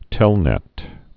(tĕlnĕt)